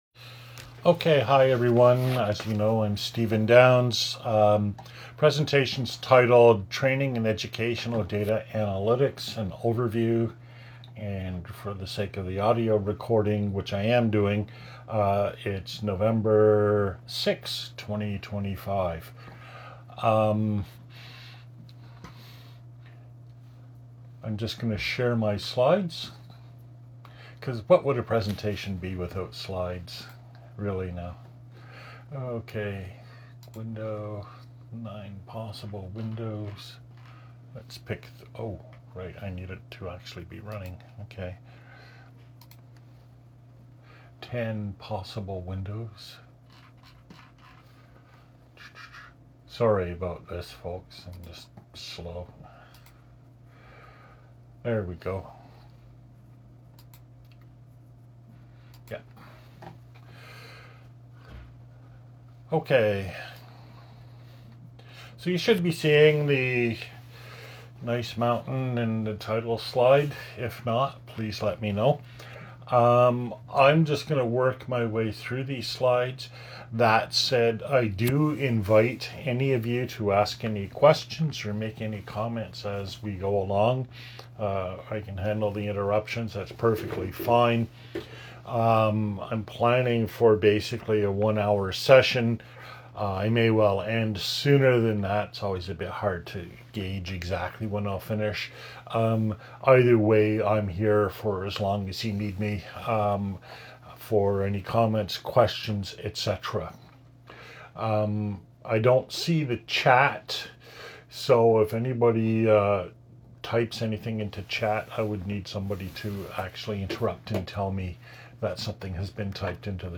This is a presentation delivered internally to project partners to provide a background and overview of learning analytics. This doesn't go deeply into any particular subject, nor does it look at recent trends, but is useful as a no-nonsense introduction to the subject.